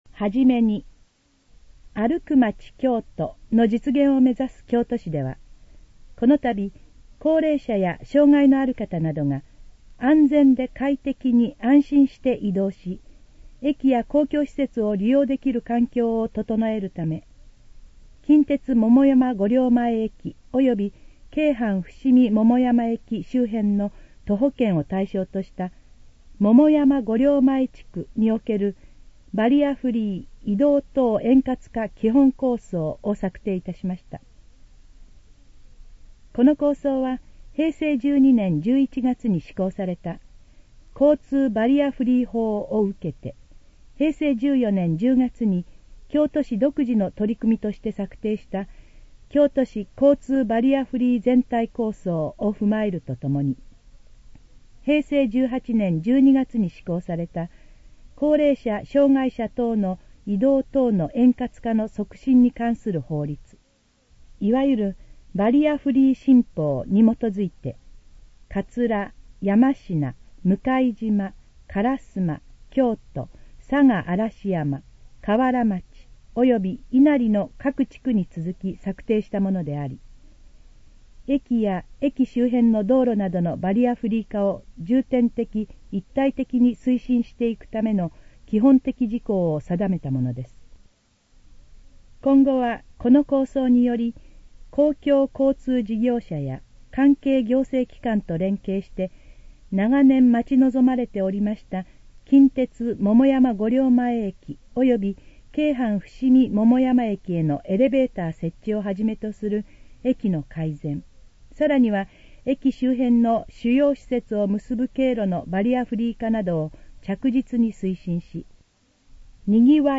このページの要約を音声で読み上げます。
ナレーション再生 約588KB